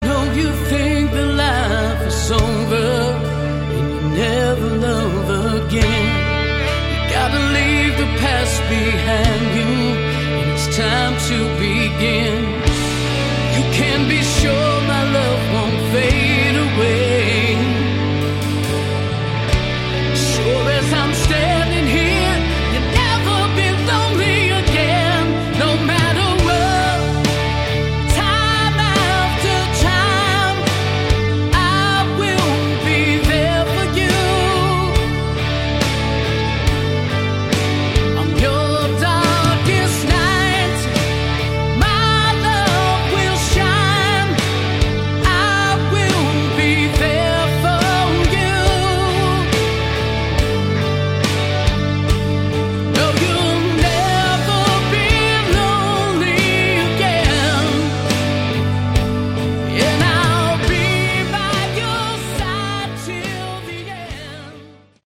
Category: AOR / Melodic Rock
vocals, guitar, drums, keyboards